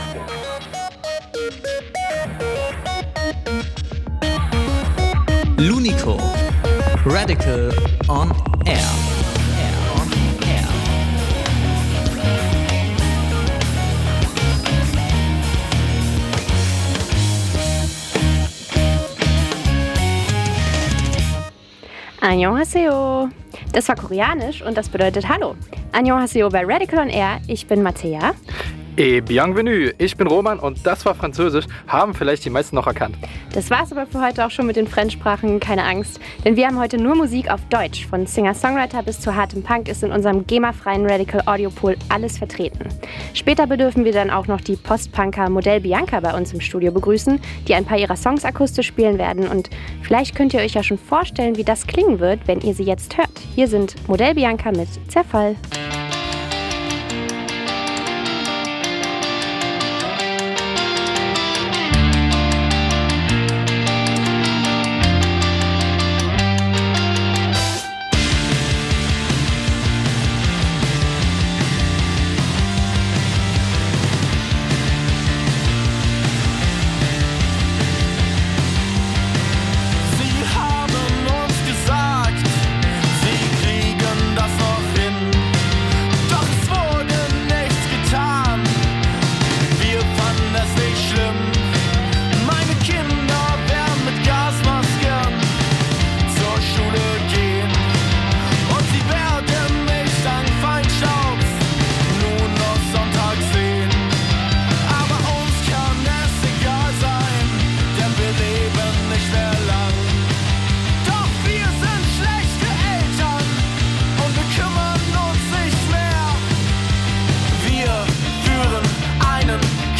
live im Studio MODELL BIANKA